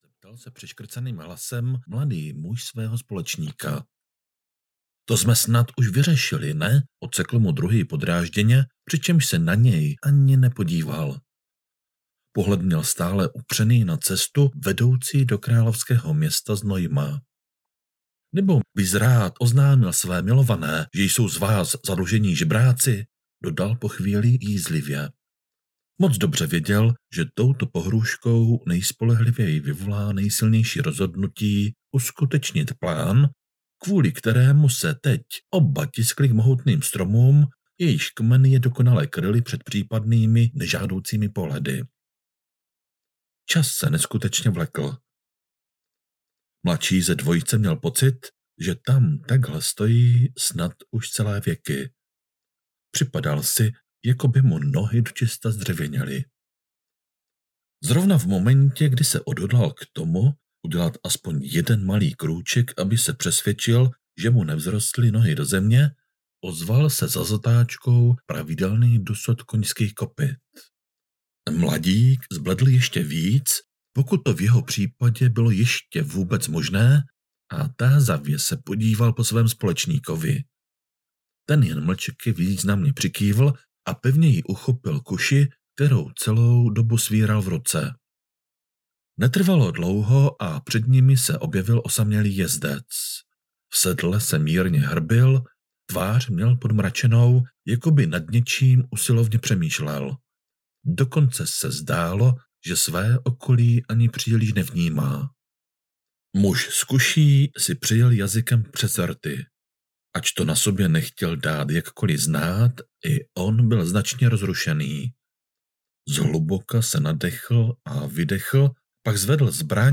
Prokletí jménem Rozálie audiokniha
Ukázka z knihy